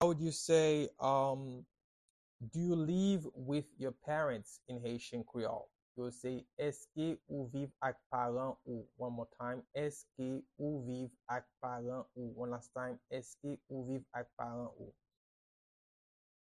Pronunciation and Transcript:
Do-you-live-with-your-parents-in-Haitian-Creole-–-Eske-ou-viv-ak-paran-ou-pronunciation-by-a-Haitian-teacher.mp3